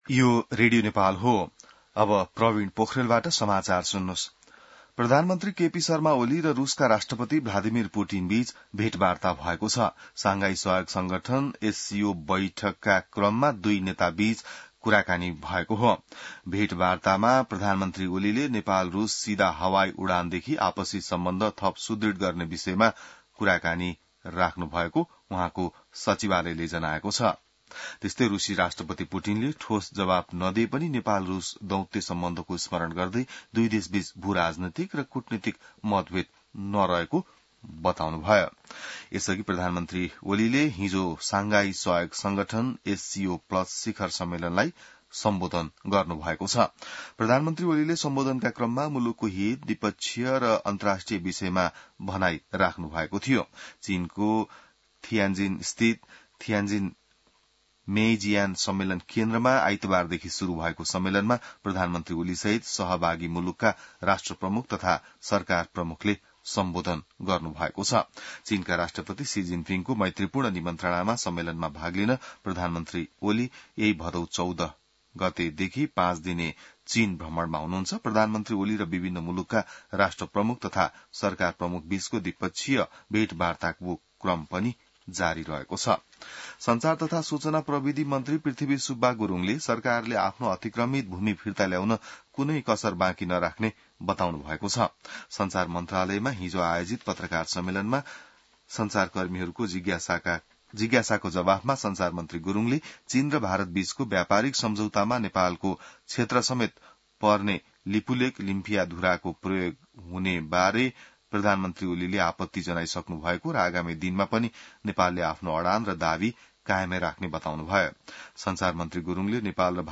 बिहान ६ बजेको नेपाली समाचार : १७ भदौ , २०८२